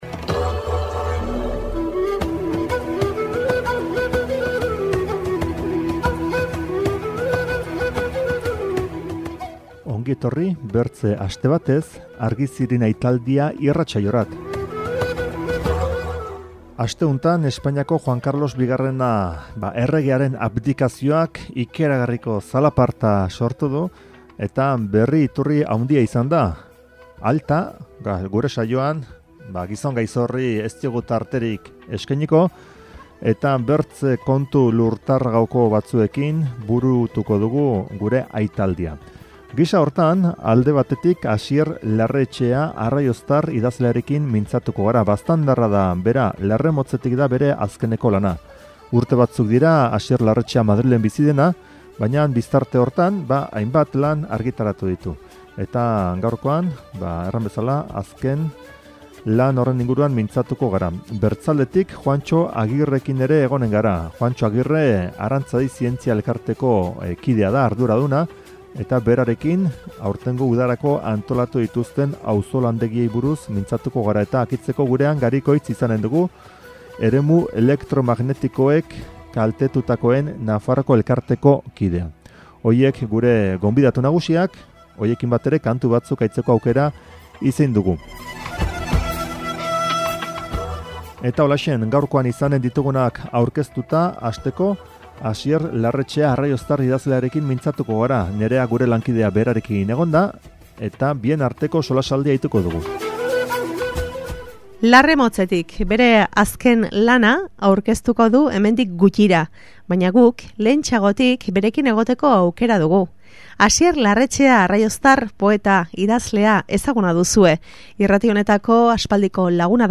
Gainera, kanta batzuk entzuteko aukera izanen dugu.